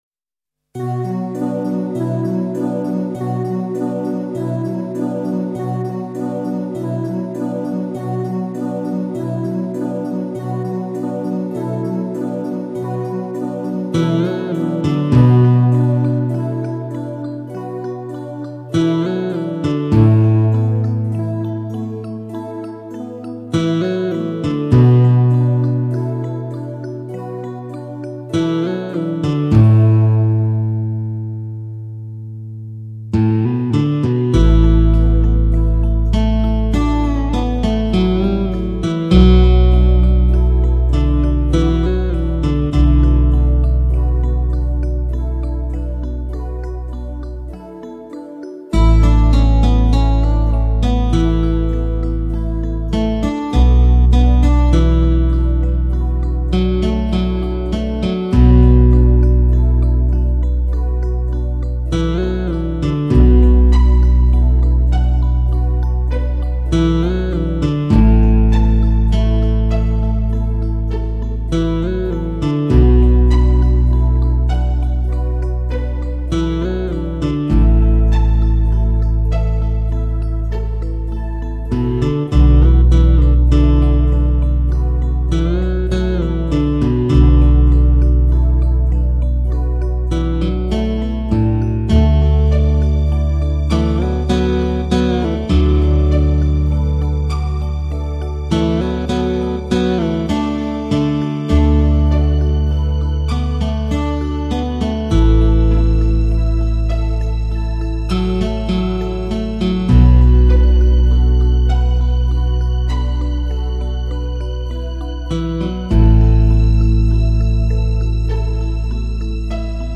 极品发烧 汽车音乐典范